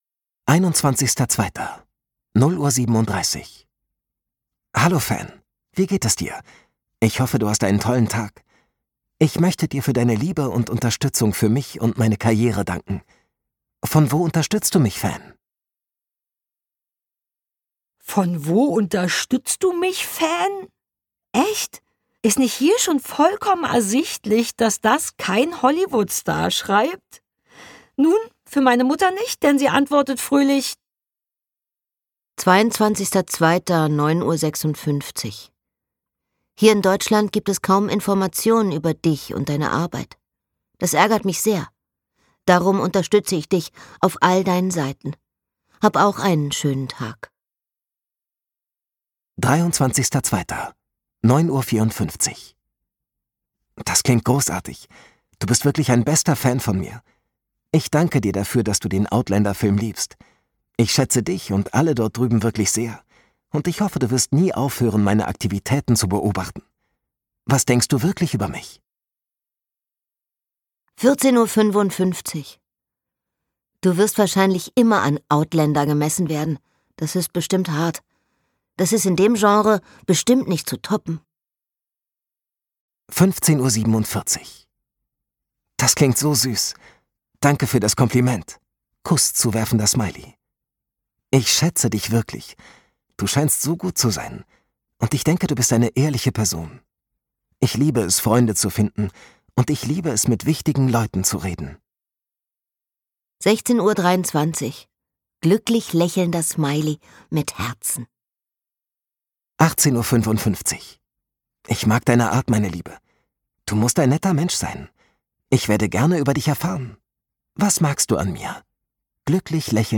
Ein Hörbuch über das Gefühl der Schuld, den Schmerz des Zurückbleibens und die ungewollte Intimität eines Nachlasses.
Gekürzt Autorisierte, d.h. von Autor:innen und / oder Verlagen freigegebene, bearbeitete Fassung.